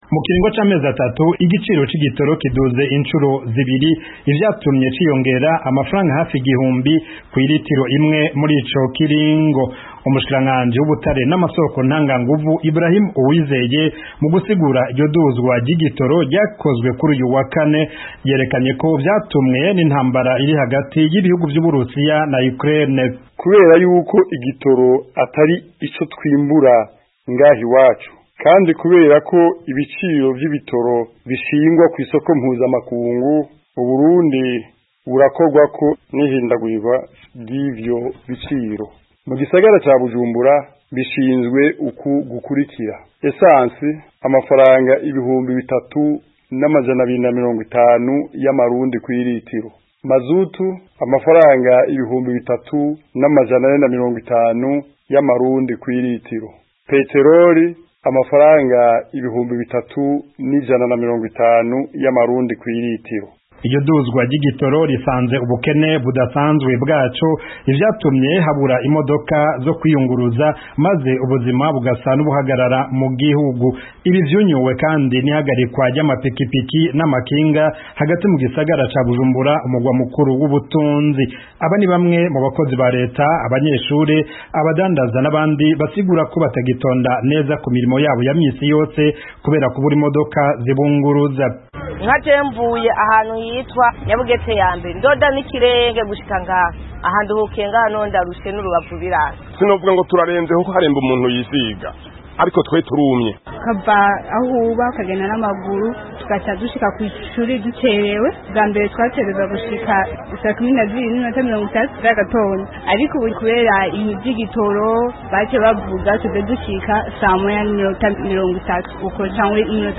ategura inkuru ushobora kumva mu ijwi rye hano hepfo